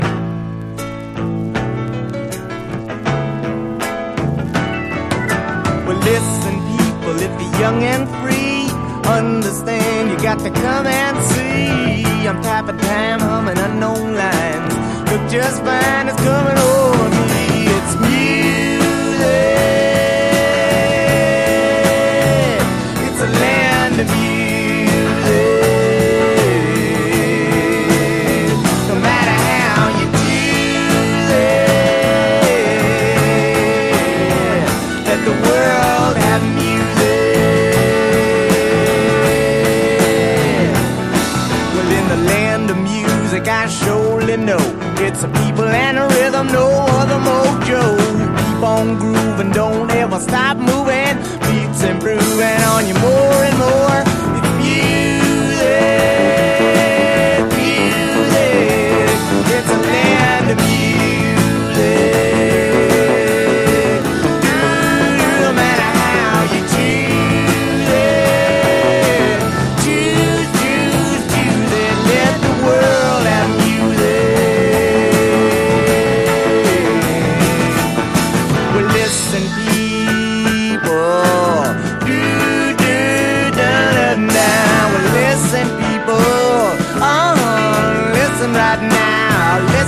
陽だまりメロウ・フォークS.S.W.必携盤！